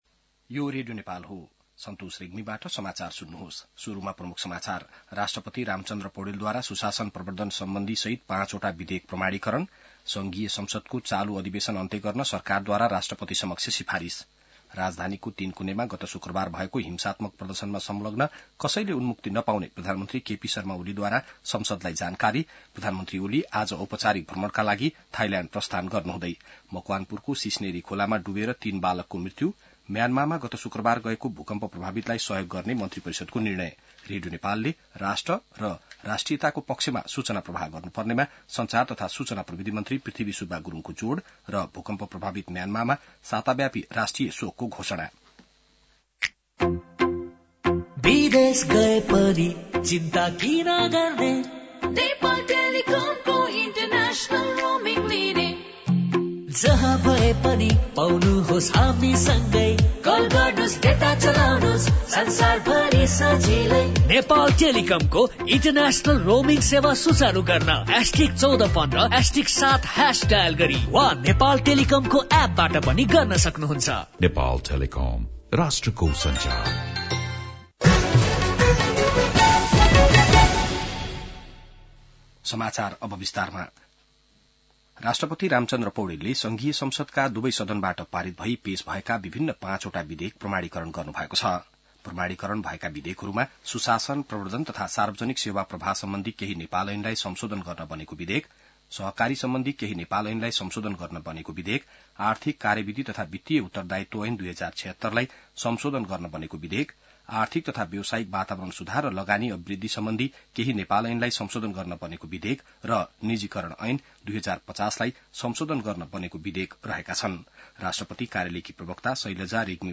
बिहान ७ बजेको नेपाली समाचार : १९ चैत , २०८१